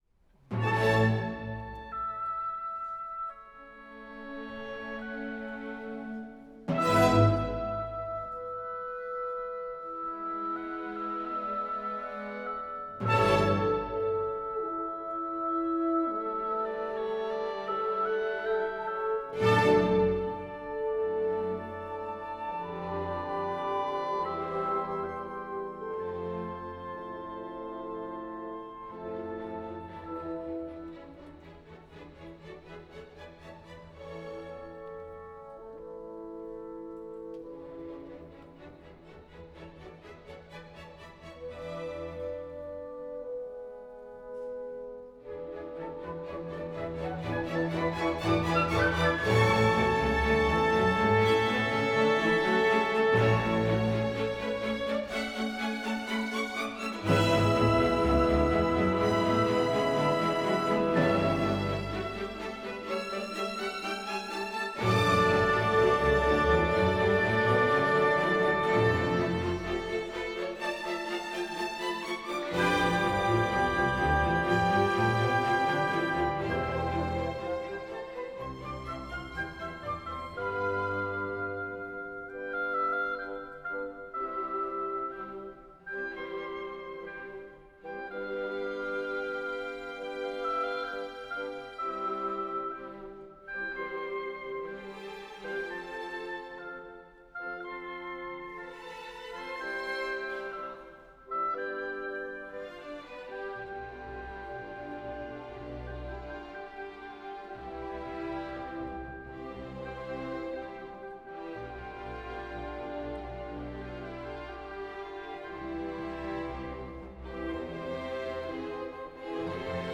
Μουσικά Σύνολα ΕΡΤ – Εθνική Συμφωνική Ορχήστρα
Δημοτικό Θέατρο Πειραιά – Δευτέρα 5 Φεβρουαρίου 2024